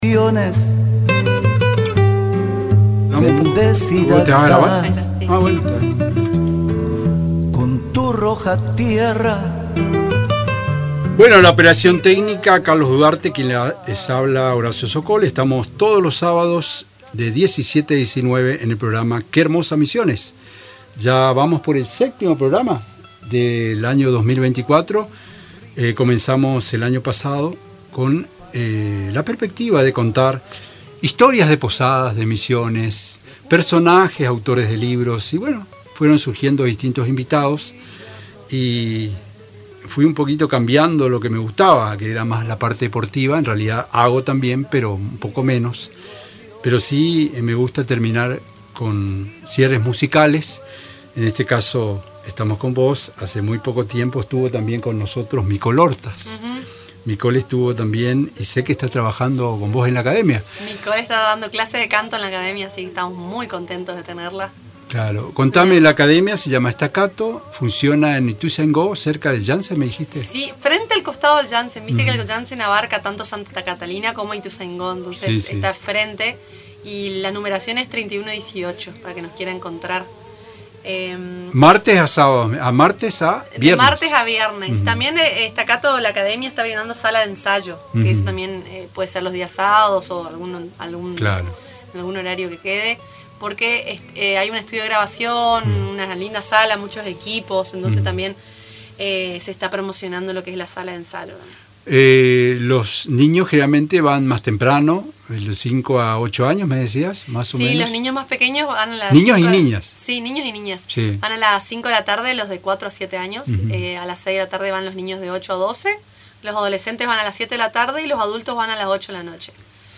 su acordeón llenó la tarde de música
Durante la entrevista